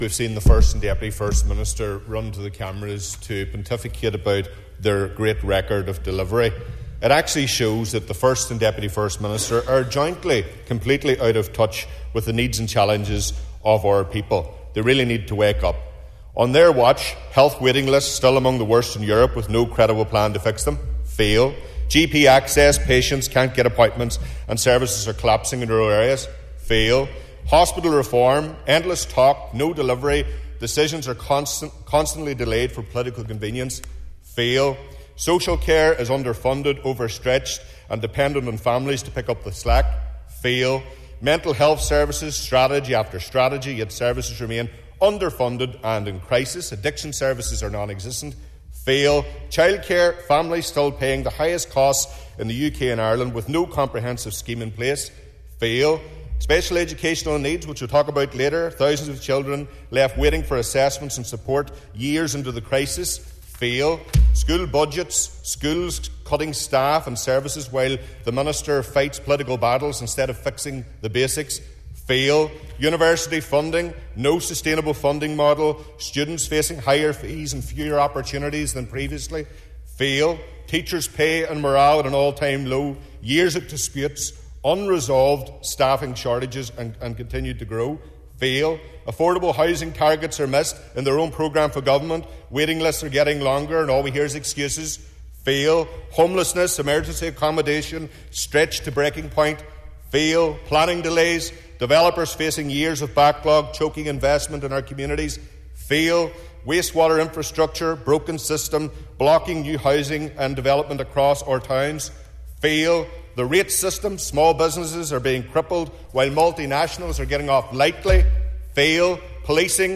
The Stormont Assembly has been told the current executive is failing across a number of areas, including health, education, transport and economic development.